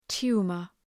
Προφορά
{‘tu:mər}